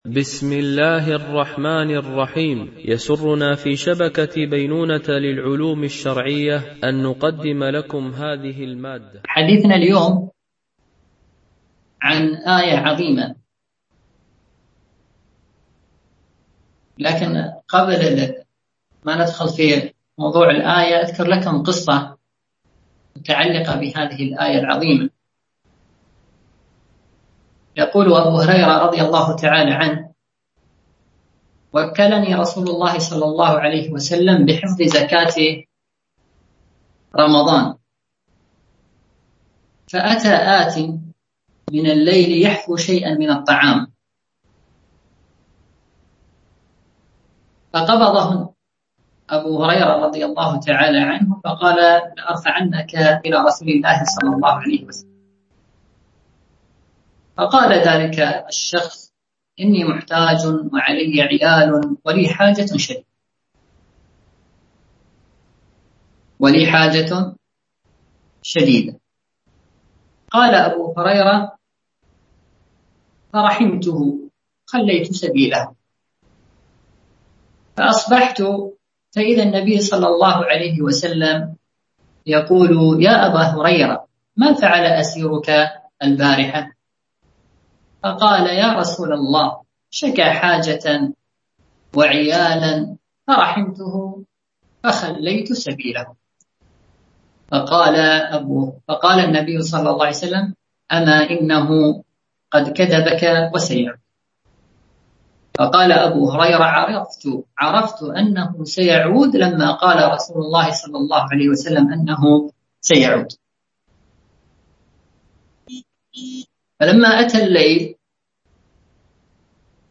سلسلة محاضرات في تفسير القرآن الكريم - المحاضرة 2 ( آية الكرسي )